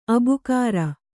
♪ abukāra